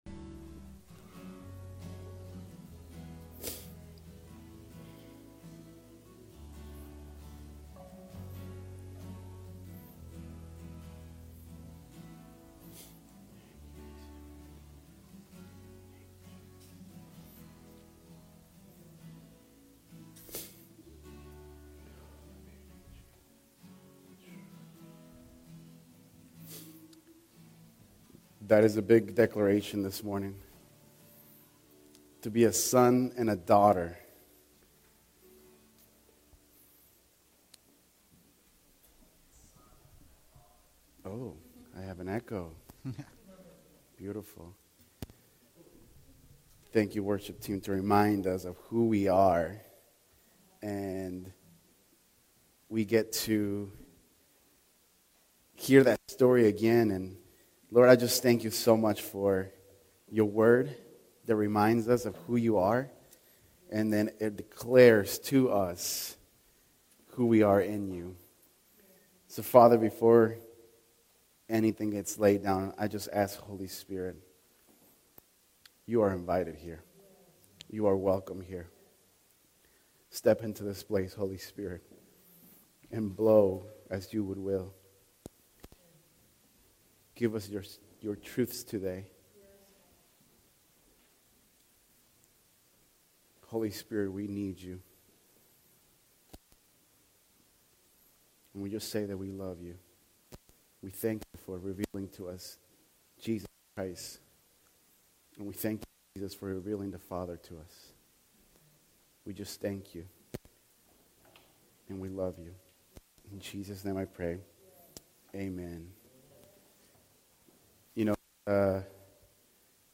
Post category: Messages